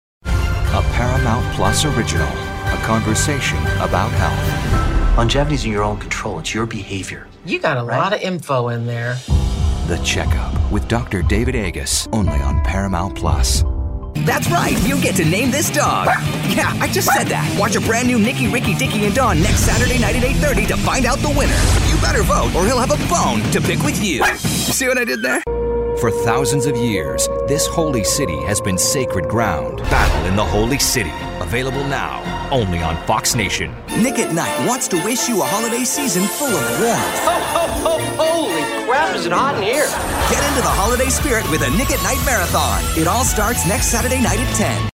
Contemporary, Real, Persuasive.
Promo